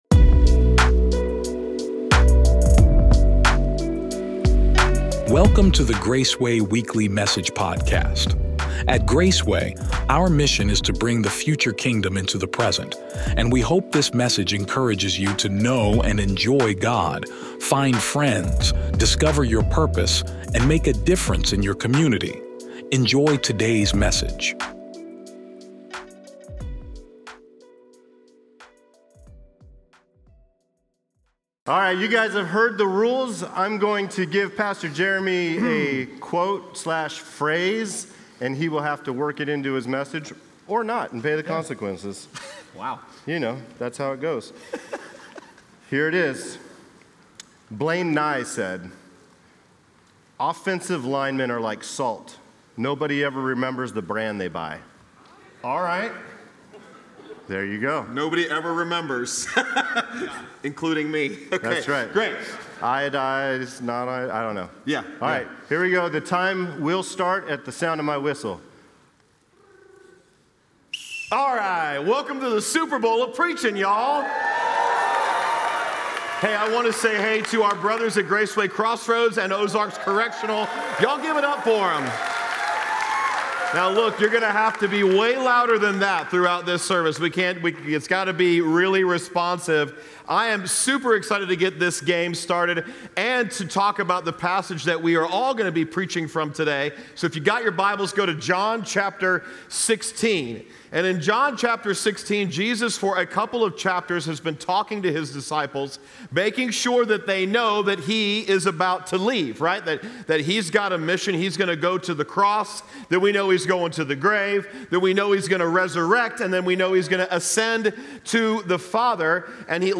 Welcome to the Super Bowl of Preaching, one of the most high-energy and unconventional Sundays of the year. This message centers on Jesus’ powerful words in John 16:33, reminding us that while trouble is a reality of life, it does not have the final word. Through passionate preaching and biblical truth, we’re challenged to guard our hearts, remember who Jesus is, and live with confident hope.